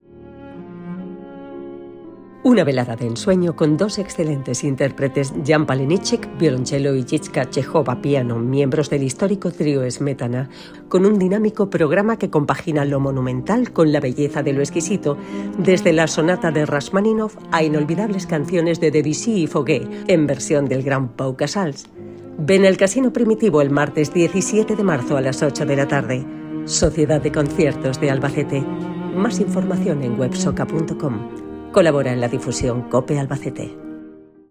CUÑA radiofónica COPE Albacete